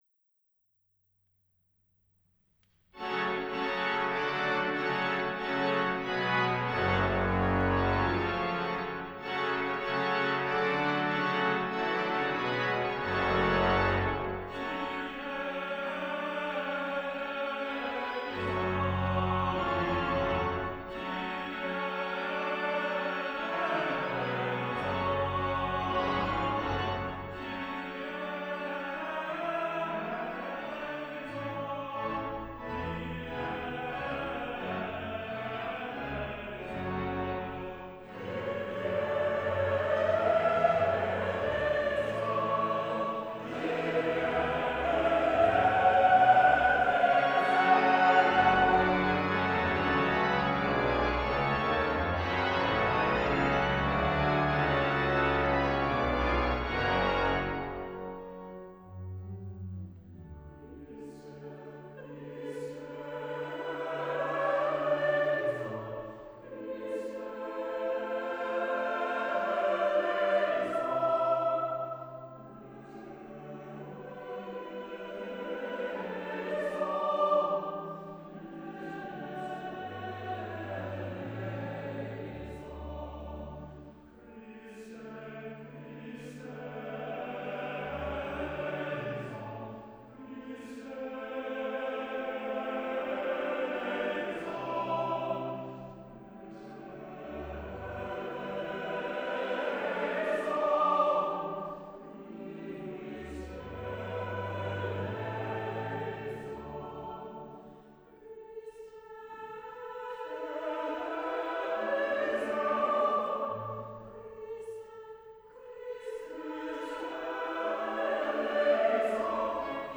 Cherwell Singers; Exeter College Chapel, Oxford, UK
Ambisonic order : H (3 ch) 1st order horizontal Recording device : MOTU Traveler + laptop
Array type : Horizontal B-format Capsule type : AKG Blue Line